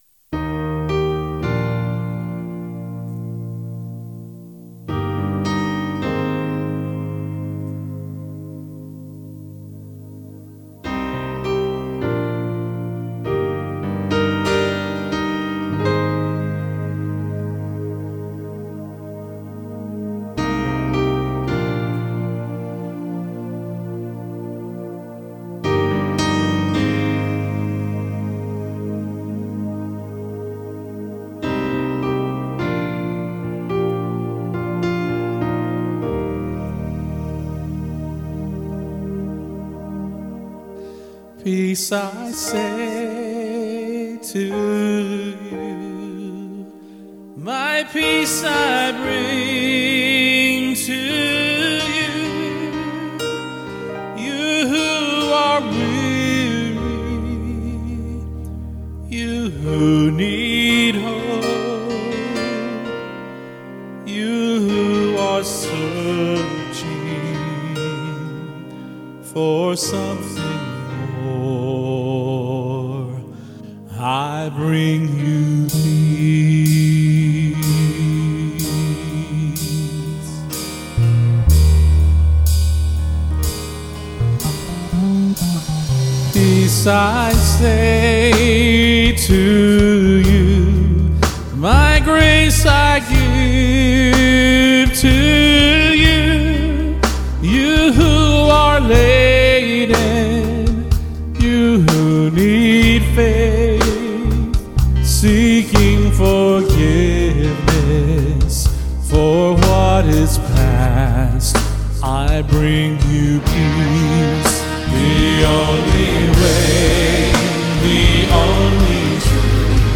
ASR-10/Guitar/ Bass